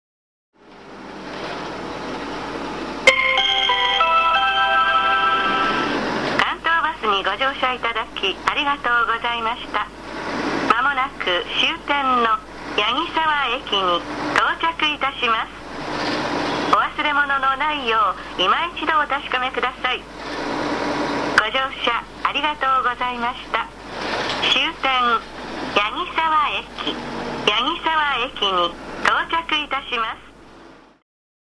一般路線バスの車内放送です。２０１１年の切り替えで「発車します」「次とまります」が男声になったほか、武蔵野営業所は２０１３年２月１５日の切り替えで終着放送のチャイムがメロディー化されています。